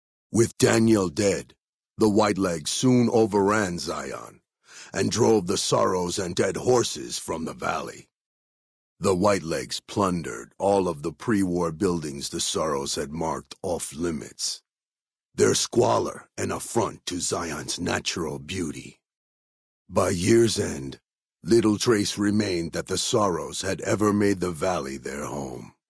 Category:Honest Hearts endgame narrations Du kannst diese Datei nicht überschreiben. Dateiverwendung Die folgende Seite verwendet diese Datei: Enden (Honest Hearts) Metadaten Diese Datei enthält weitere Informationen, die in der Regel von der Digitalkamera oder dem verwendeten Scanner stammen.